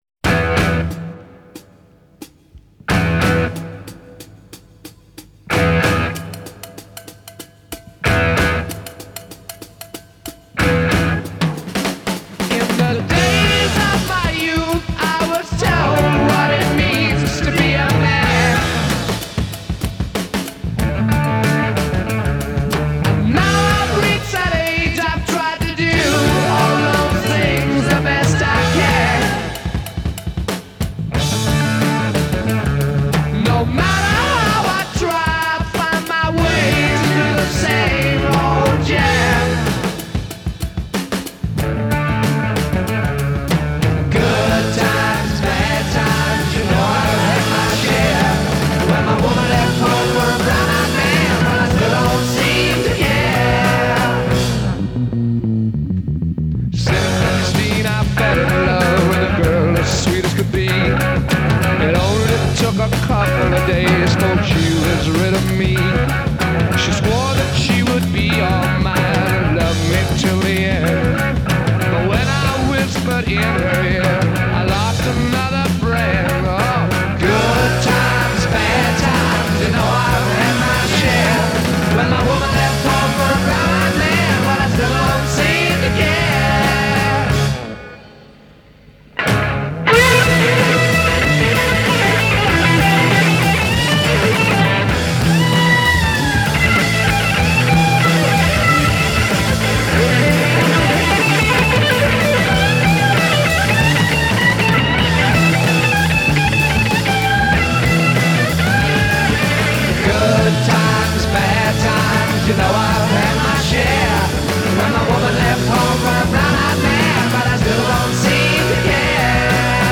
Genre: Rock
Style: Blues Rock, Hard Rock, Arena Rock